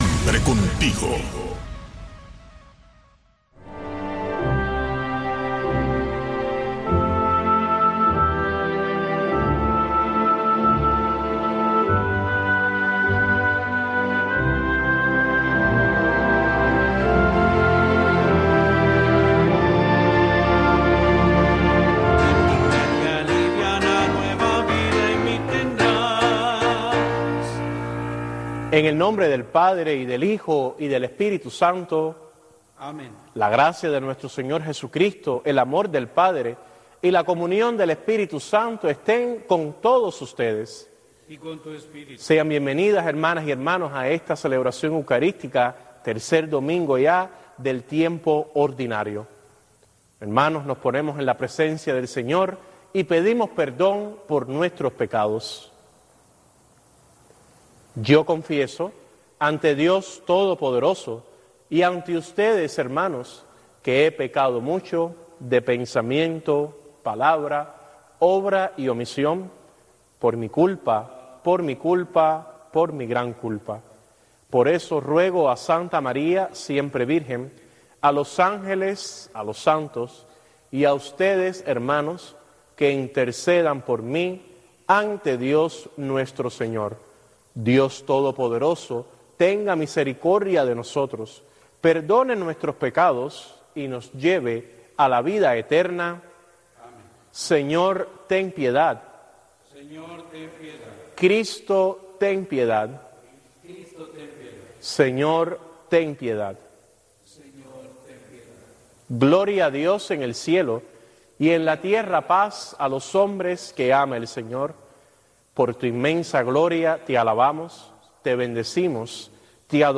La misa dominical transmitida para Cuba desde el Santuario Nacional de Nuestra Señor de la Caridad, un templo católico de la Arquidiócesis de Miami dedicado a la Patrona de Cuba.